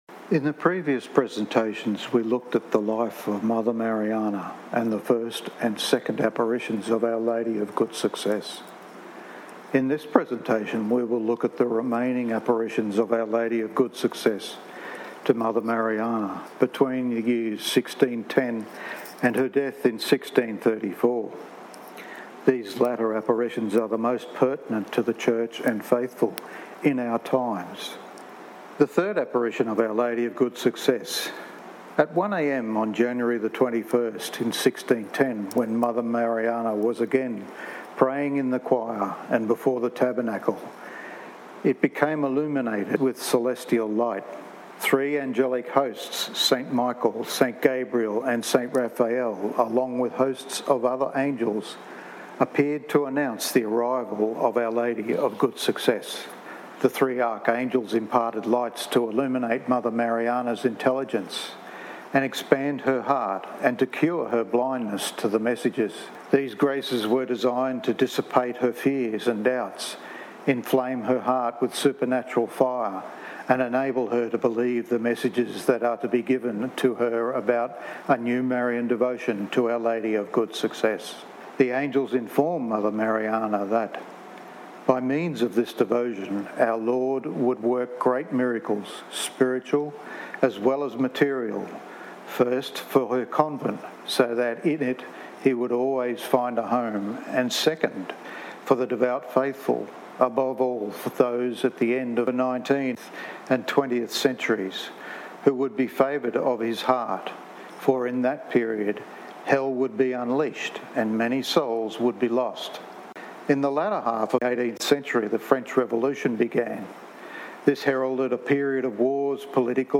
prepared and narrated